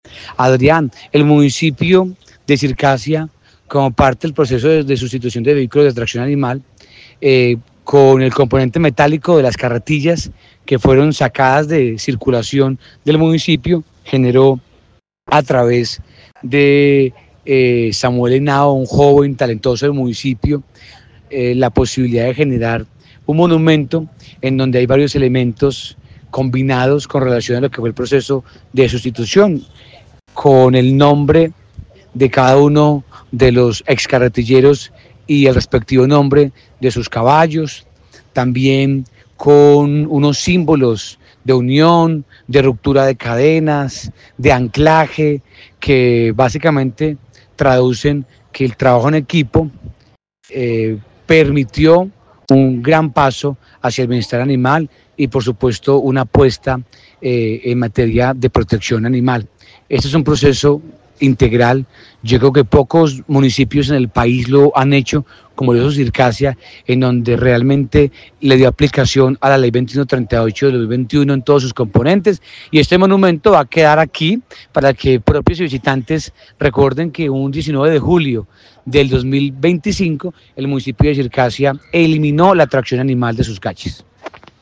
Julián Peña, alcalde de Circasia, Quindío